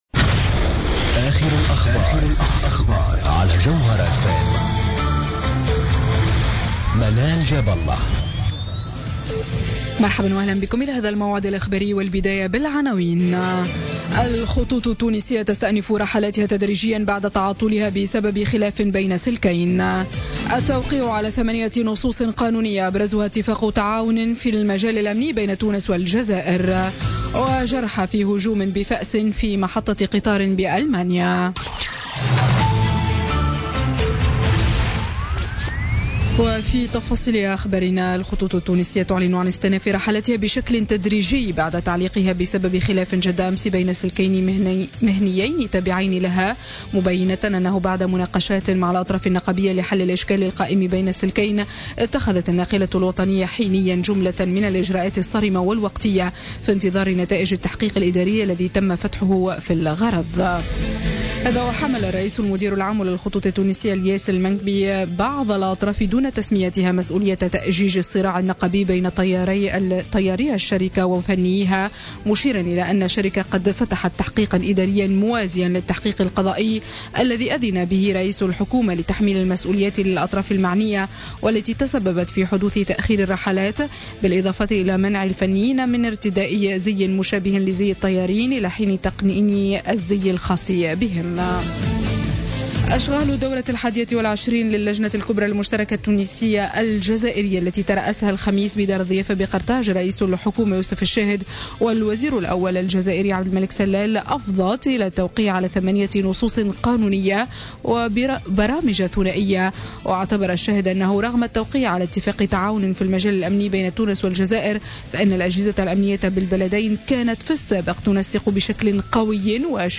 نشرة أخبار منتصف الليل ليوم الجمعة 10 مارس 2017